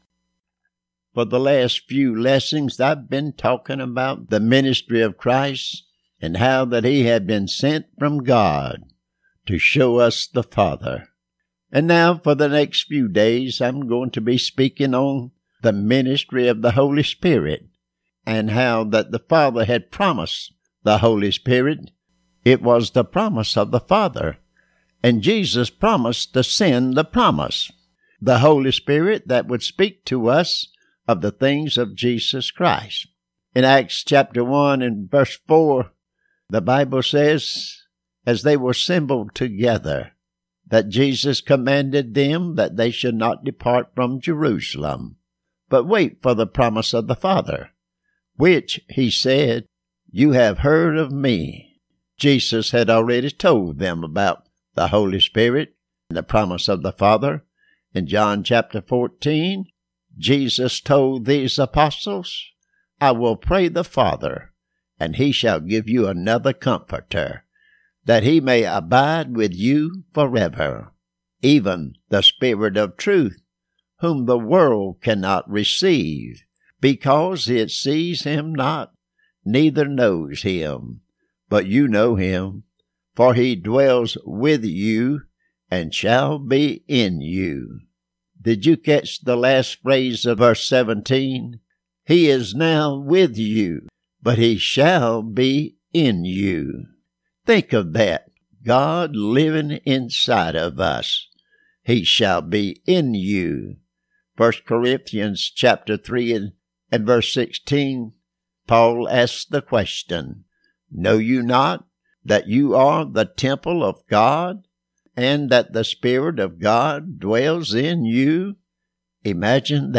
Lesson For Wednesday